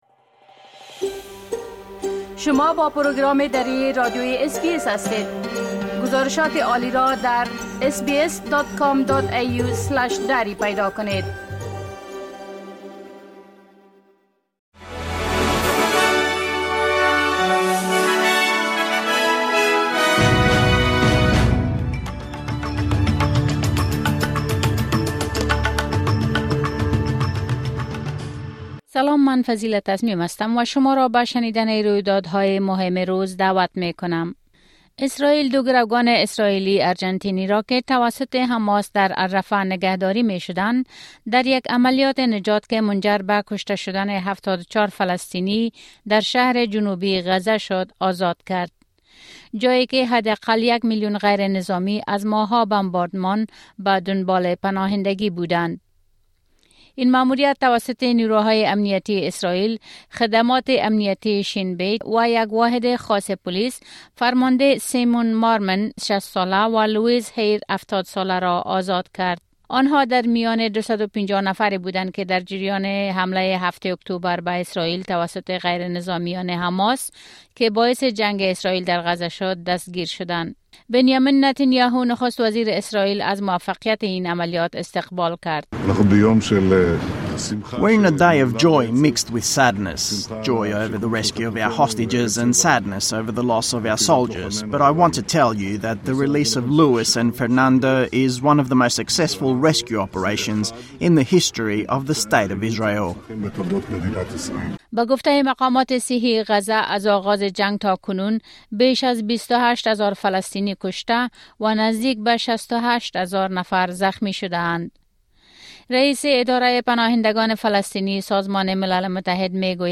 خلاصه اخبار مهم روز از اس‌بی‌اس دری